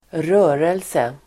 Uttal: [²r'ö:relse]